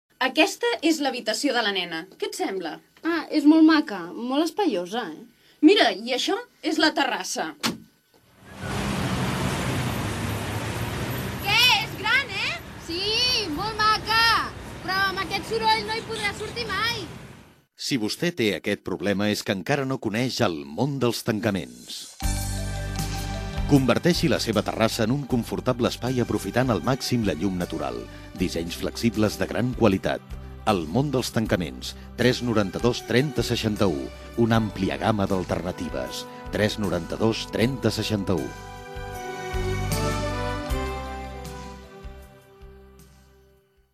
Gènere radiofònic Publicitat Anunciant El món dels tancaments